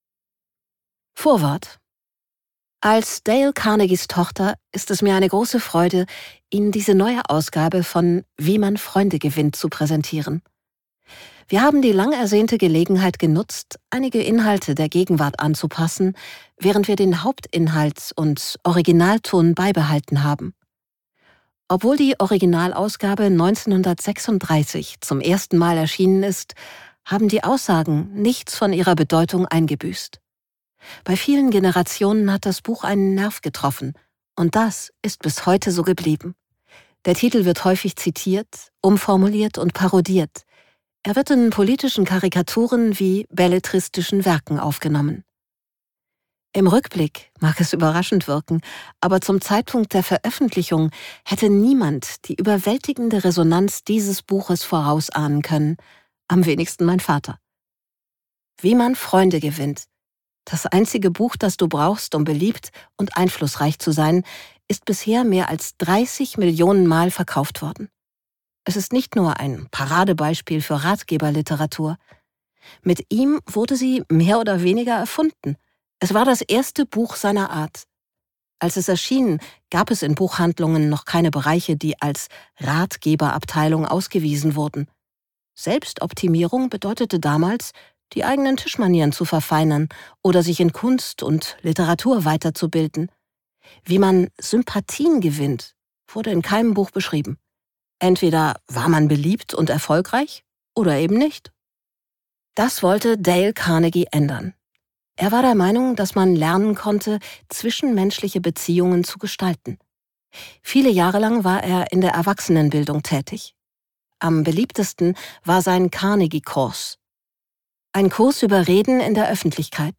Wie man Freunde gewinnt - Dale Carnegie | argon hörbuch
Gekürzt Autorisierte, d.h. von Autor:innen und / oder Verlagen freigegebene, bearbeitete Fassung.